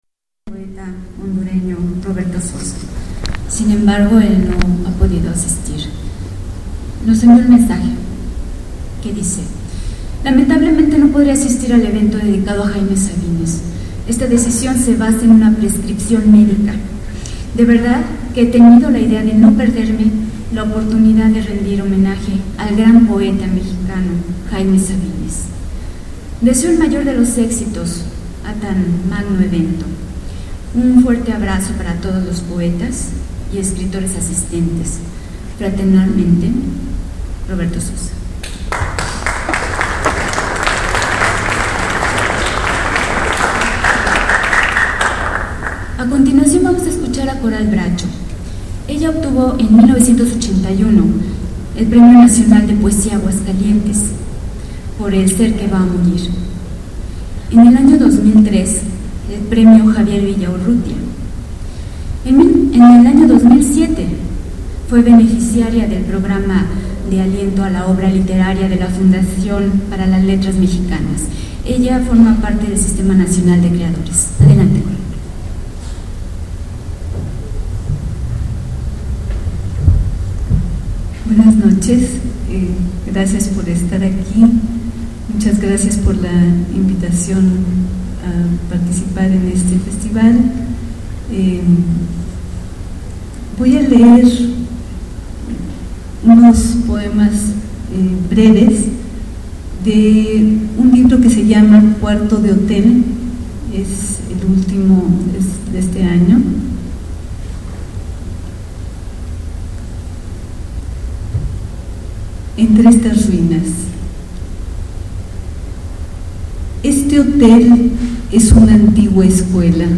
Lugar: Teatro Daniel Zebadúa de San Cristóbal de Las Casas, Chiapas. Mexico. Equipo: iPod 2Gb con iTalk Fecha: 2008-11-10 12:03:00 Regresar al índice principal | Acerca de Archivosonoro